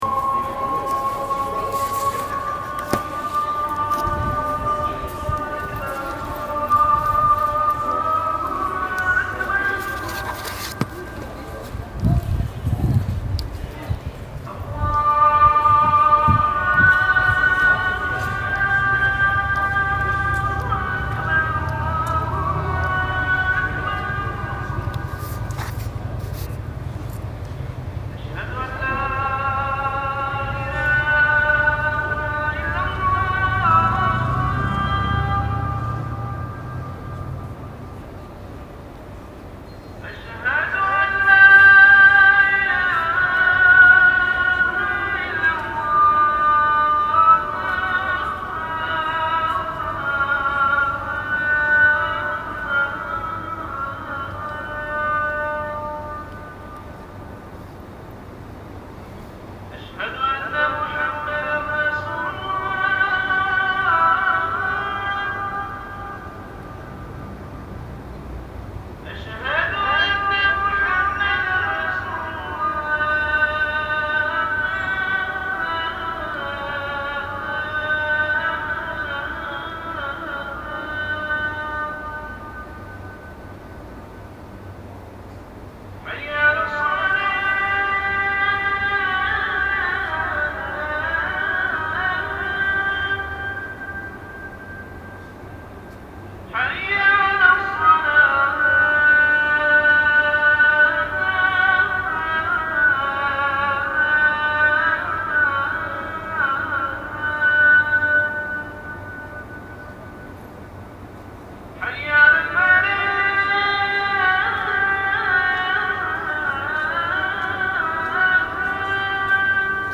Abu Dhabi. Call to Prayer – Isha 8:34 pm.  (Apologies – first 27 seconds spent getting away from noisy people. It is really beautiful after that.) Also from yesterday evening, recorded on my laptop in a parking lot near a mosque.